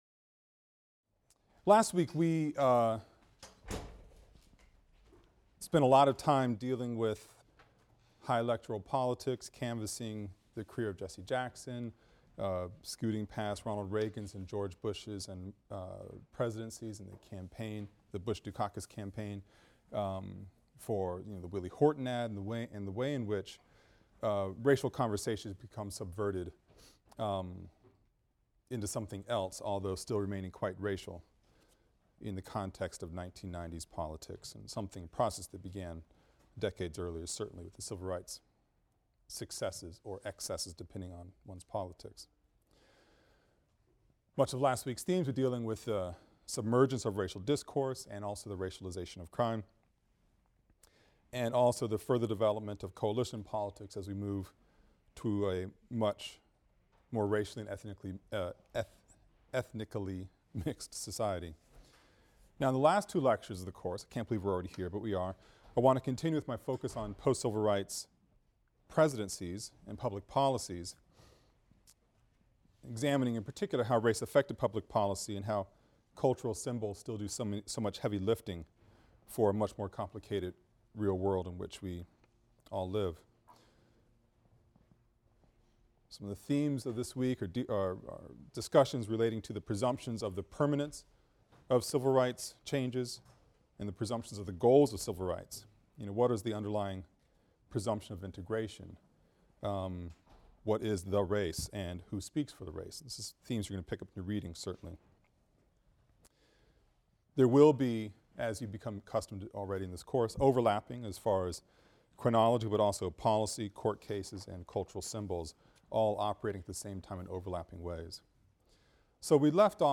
AFAM 162 - Lecture 24 - Who Speaks for the Race?